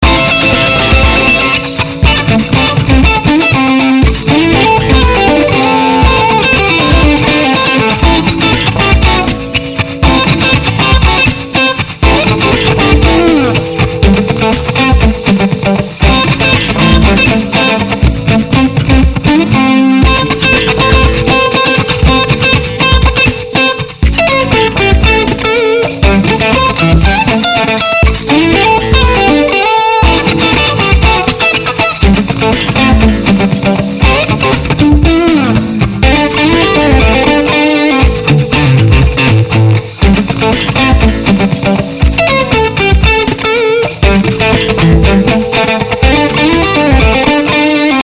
Jingles to listen to:
synthétiseur, chant, guitare, percussion, drums